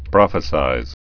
(prŏfĭ-sīz)